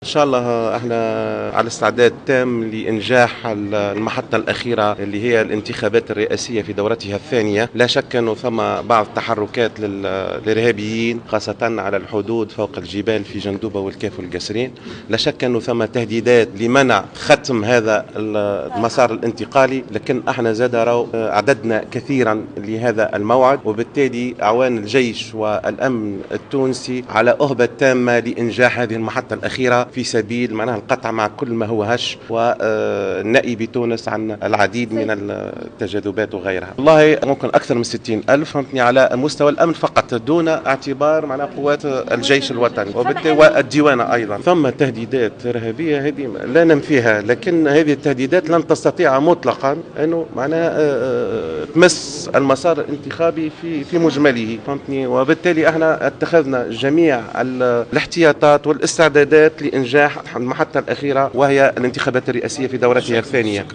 أكد وزير الداخلية لطفي بن جدو اليوم الاربعاء على هامش المؤتمر 38 لقادة الشرطة والأمن العرب أن أكثر من 60 ألف عون أمن سيؤمنون الانتخابات الرئاسية في دورتها الثانية.